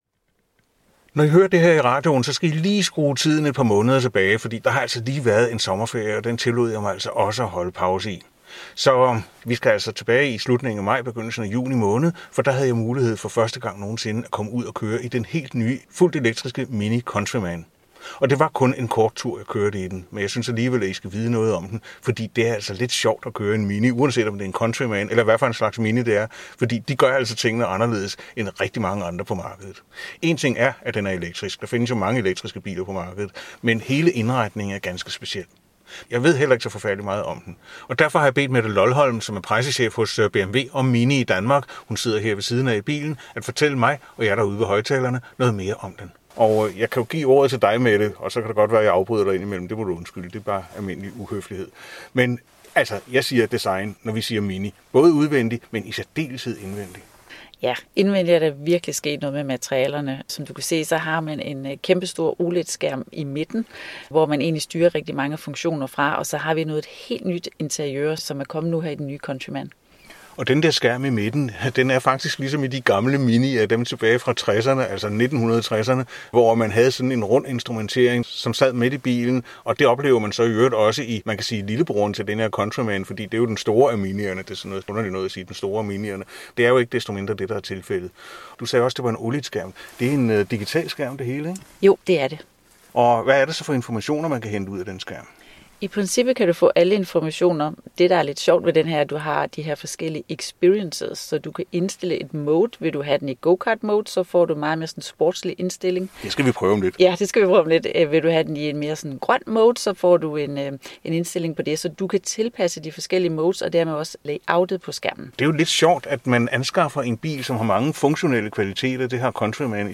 Kort test: MINI Countryman. Intw